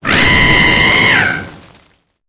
Cri de Pyrobut dans Pokémon Épée et Bouclier.